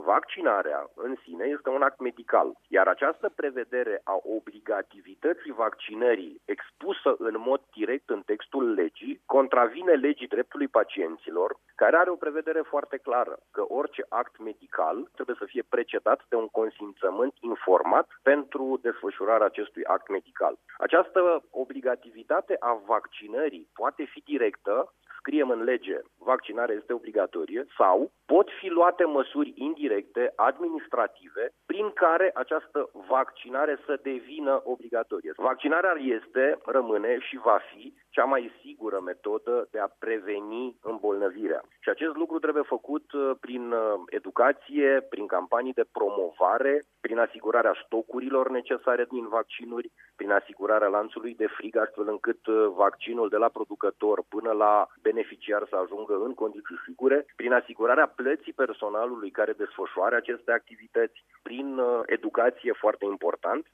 Invitat în această dimineaţă la Radio România Actualităţi, parlamentarul de Mureș a precizat că Legea vaccinării a trecut de Senat şi se află la Camera Deputaţilor unde au loc dezbateri ample în ceea ce priveşte obligativitatea imunizării: